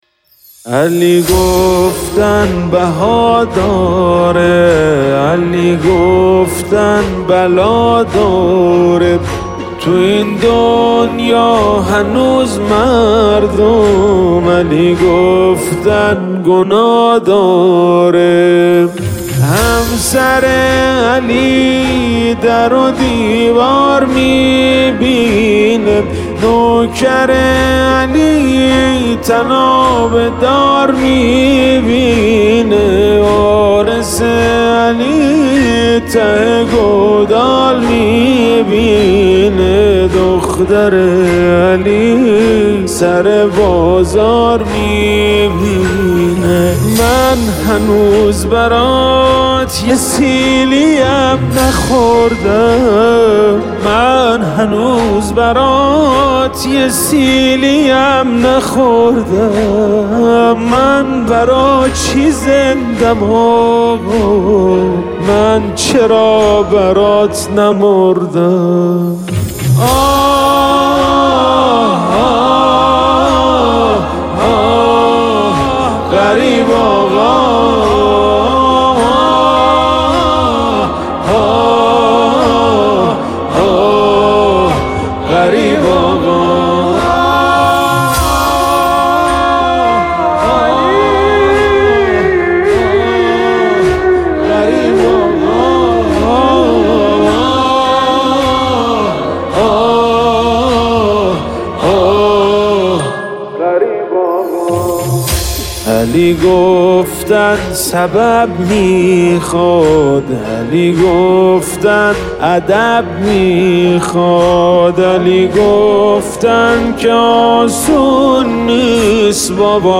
نماهنگ مداحی